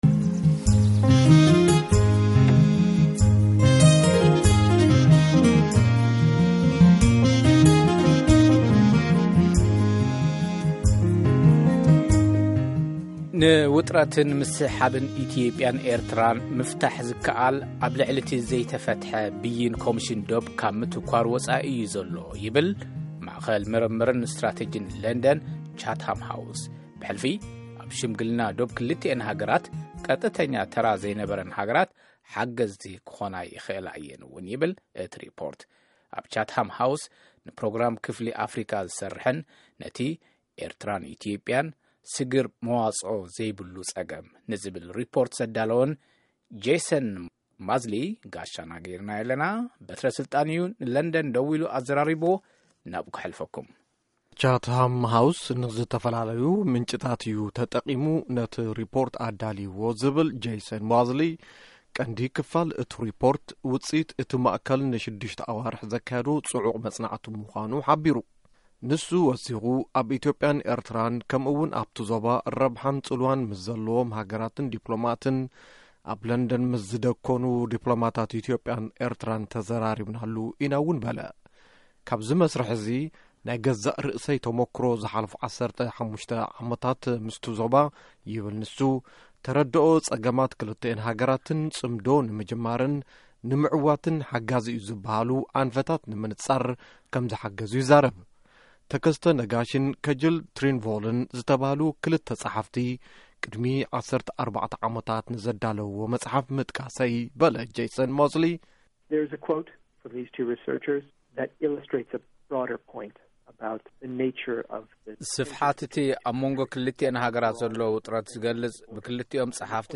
ሙሉእ ቃለ-ምልልስ ምስ ቻትሃም ሃውስ